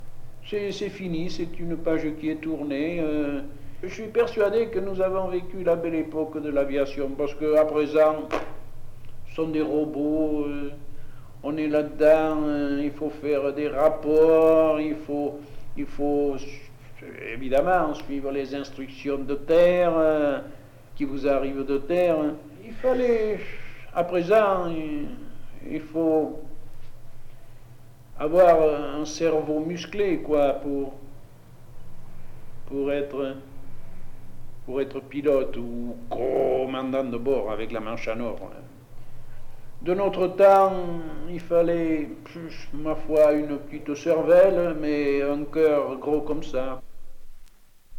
Cet enregistrement est constitué par les récits de onze témoins qui vécurent entre 1918 et 1933, la conquête aérienne de l’Atlantique Sud